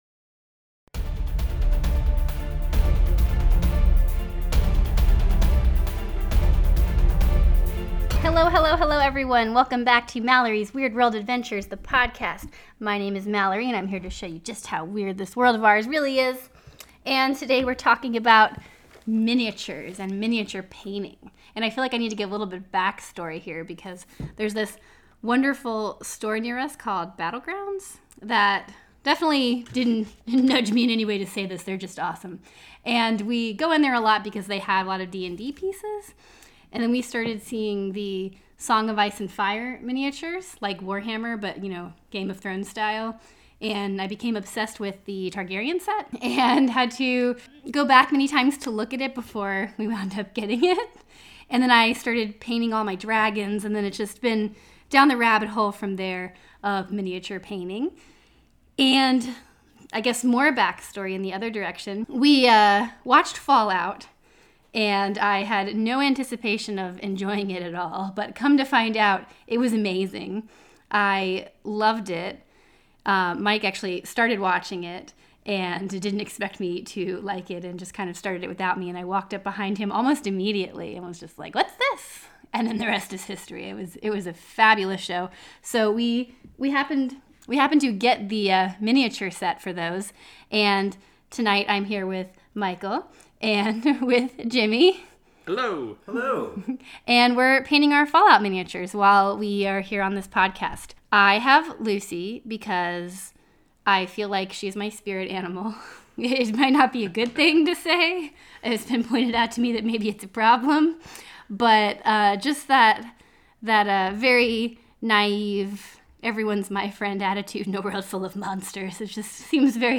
A Nerdy Conversation While Painting Miniatures